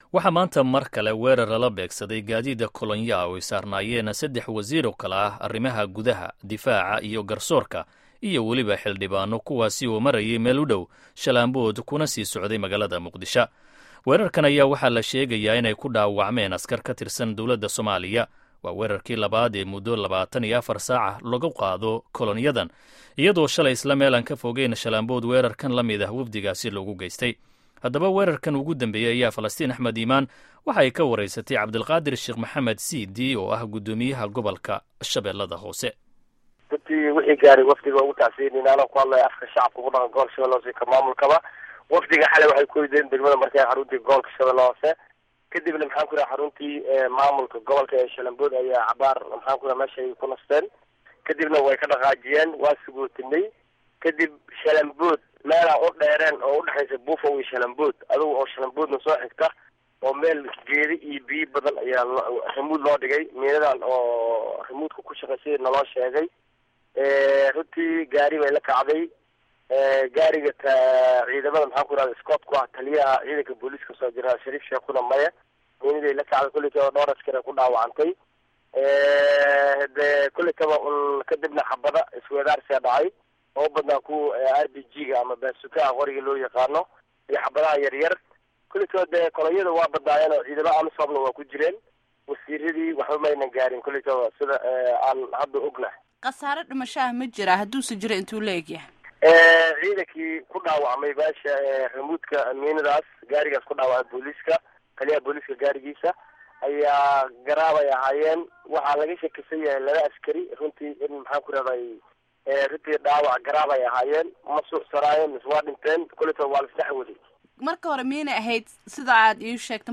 Wareysiga Guddoomiyaha Sh Hoose.